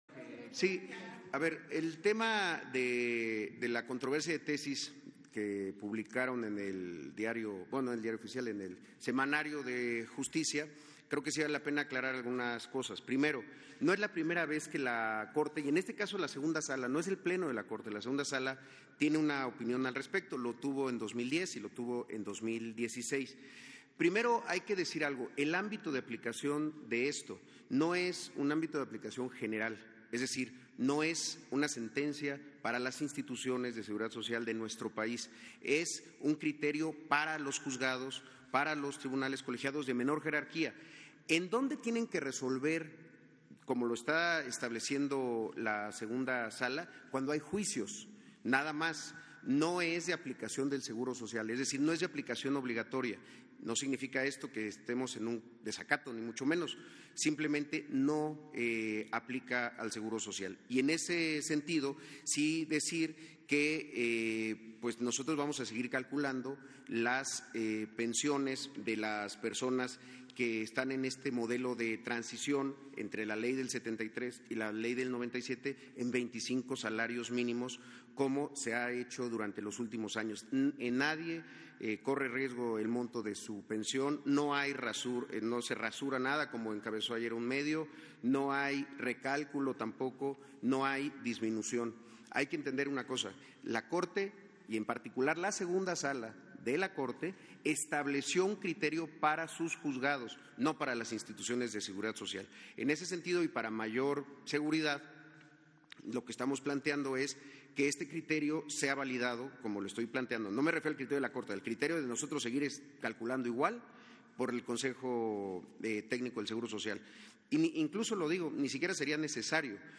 Durante la conferencia matutina que encabezó el Presidente de México, Andrés Manuel López Obrador, el titular del Seguro Social aseguró que “nadie corre riesgo en el monto de su pensión, no se rasura nada como ayer encabezó un medio, no hay recálculo, no hay disminución”.